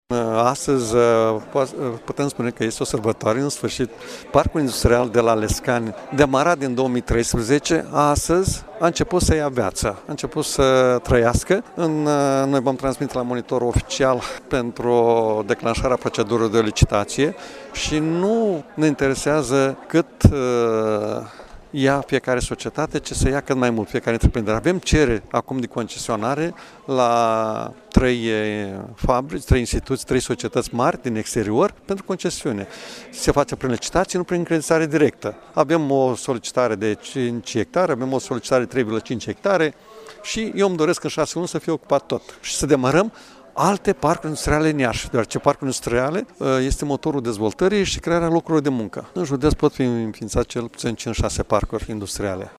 Președintele consiliului județean Iași, Maricel Popa